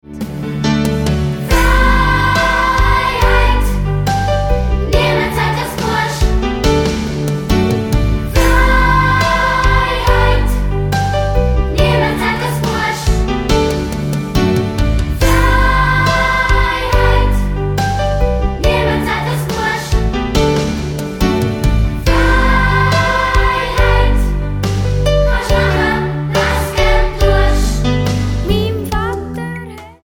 Musical - CD mit Download-Code